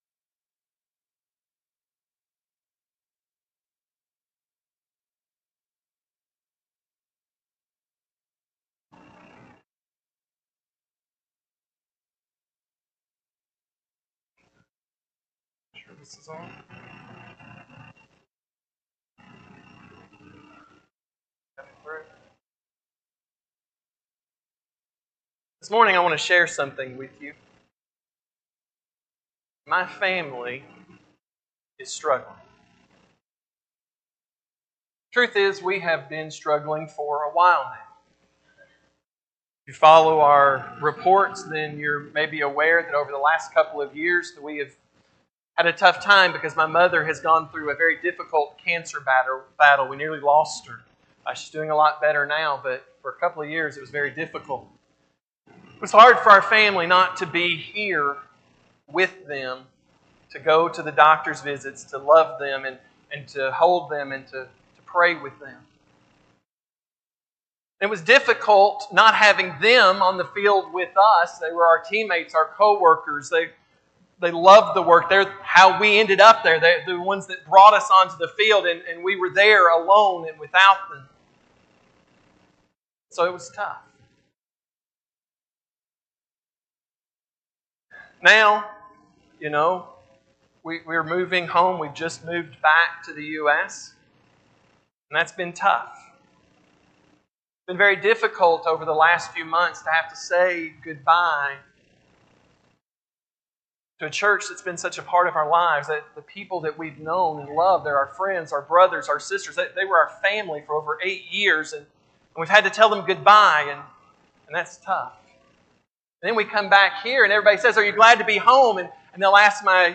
Sunday AM Sermon
6-8-25-Sunday-AM-Sermon.mp3